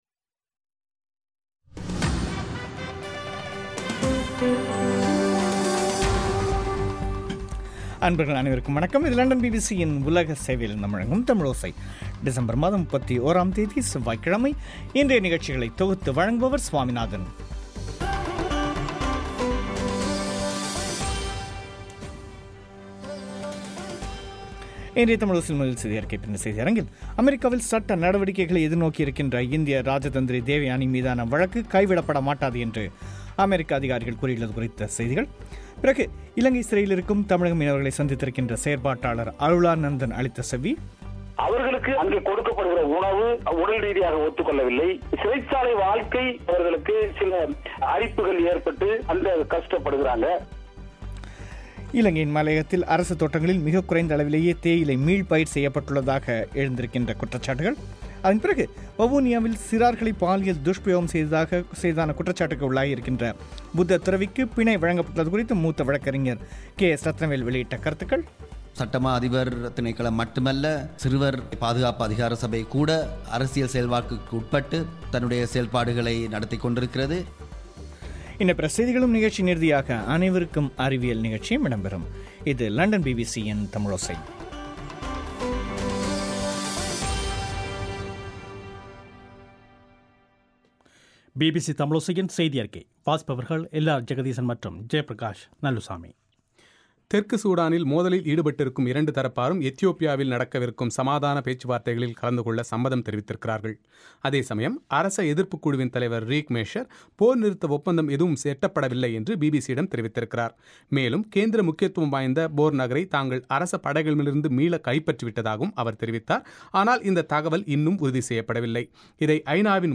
இன்றைய தமிழோசையில் முக்கிய செய்திகள்